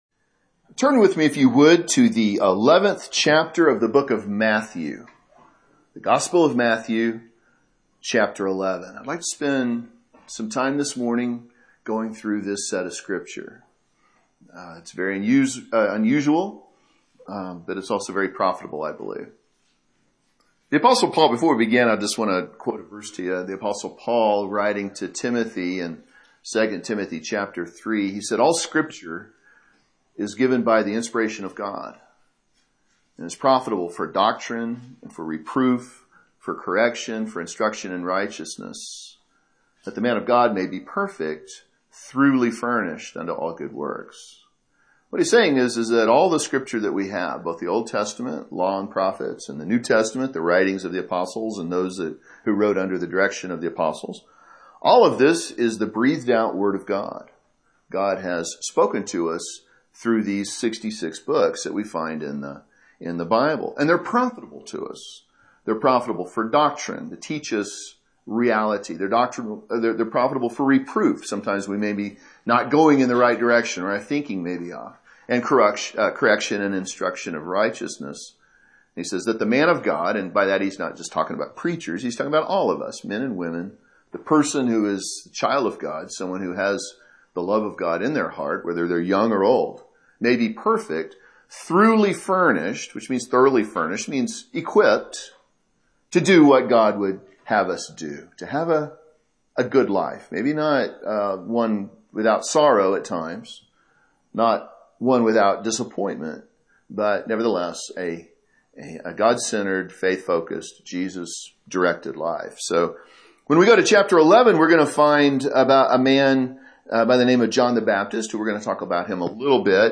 Sermons preached in other churches • Page 13
This sermon was recorded at Oxford Primitive Baptist Church Located in Oxford,Kansas